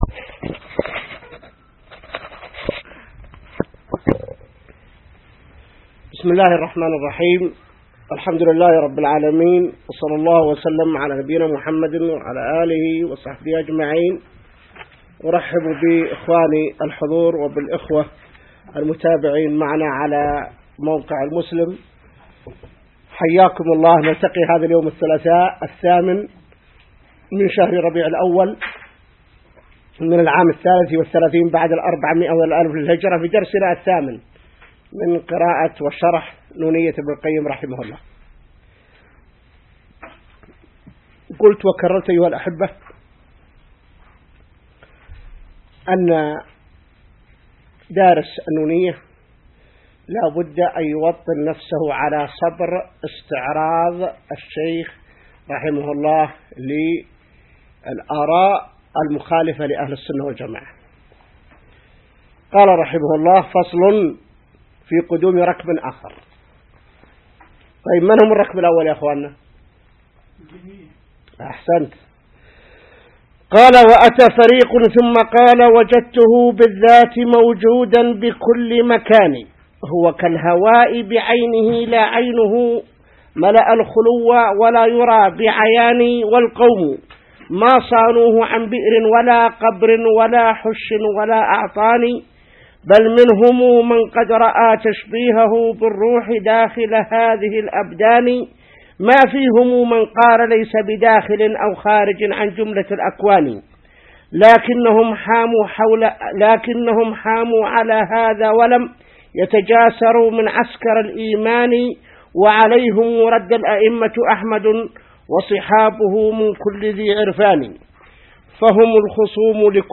الدرس الثامن من شرح نونية ابن القيم